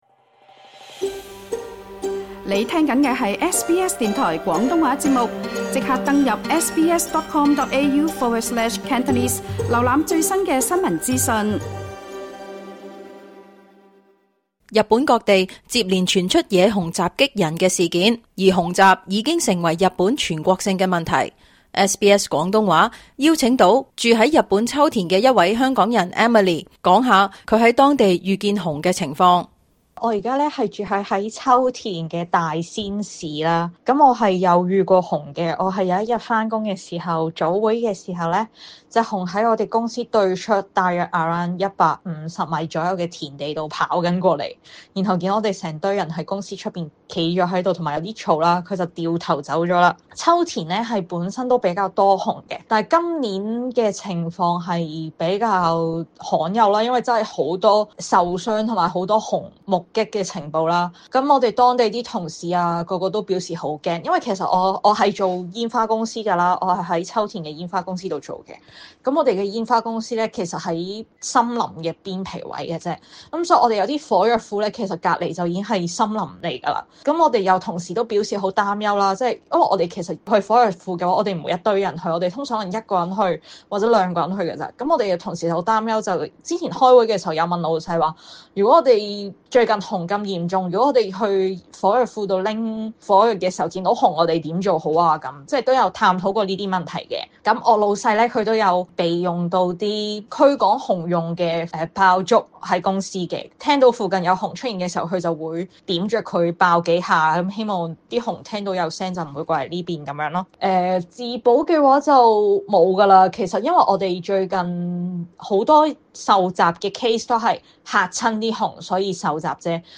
日本各地近日熊襲事件激增，居日港人向 SBS 廣東話講述親歷遇熊及野豬經過。
居於秋田與東京的港人接受 SBS 廣東話訪問，分別講述親歷及觀察到熊或野豬接近人類活動範圍，他們亦分享當地政府及個人如何應對「熊襲」。